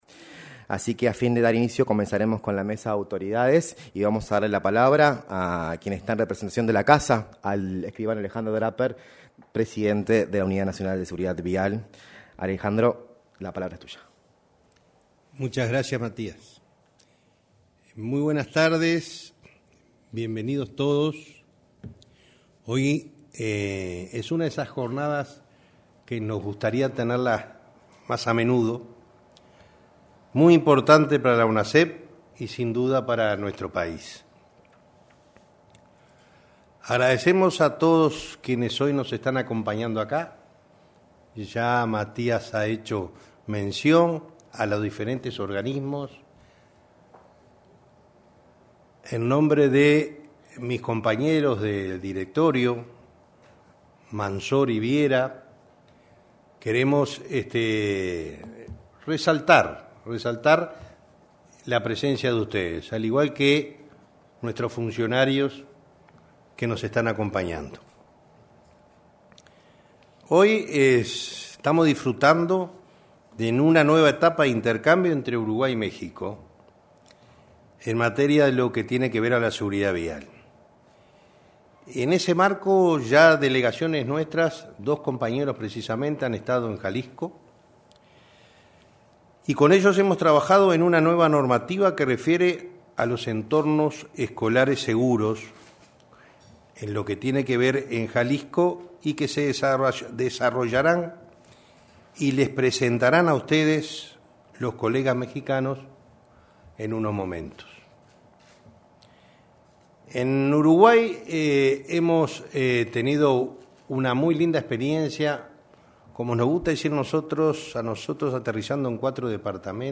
Palabras de el Sr.Alejandro Draper, presidente de UNASEV y el Sr. Mariano Berro, Director de AUCI.